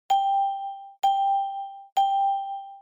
Music Flight用に作ってあった3秒のdingファイルにベルの画像を追加してみると見事にドクターヘリ（BK-117C-2）に替わりました。
ding3.wma